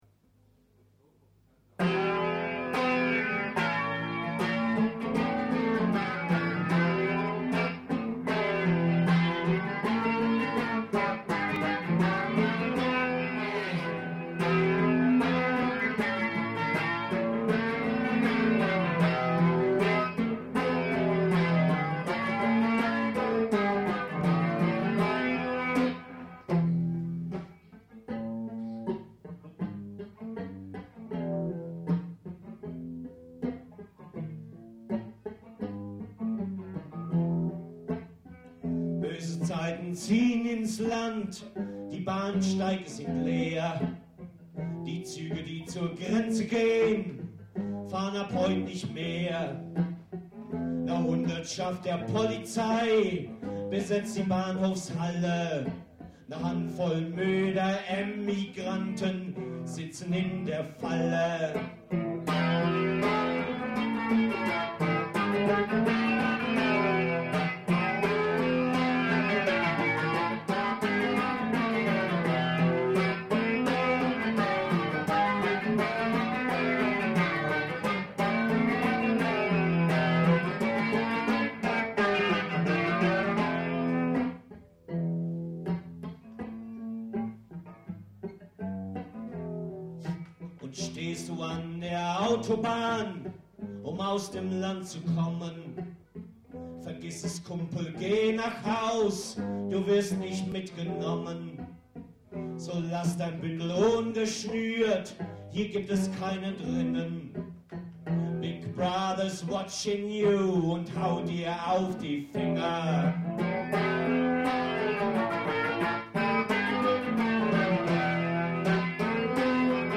"LIVE"